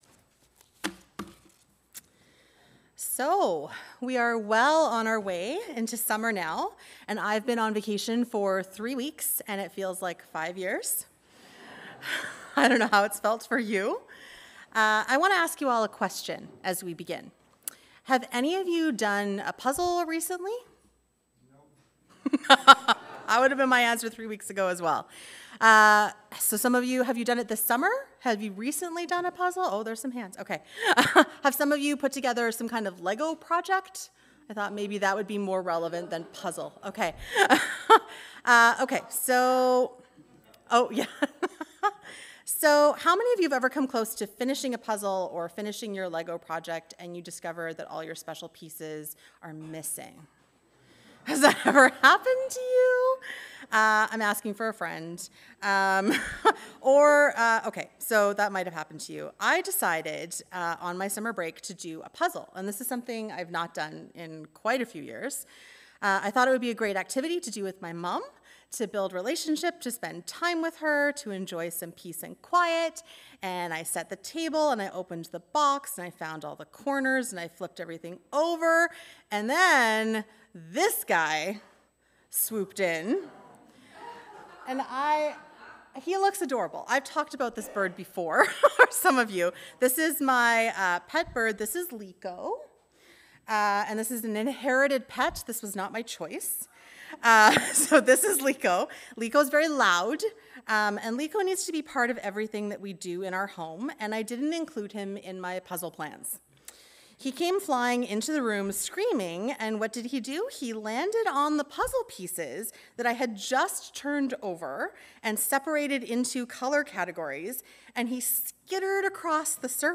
Sermons | Olivet Baptist Church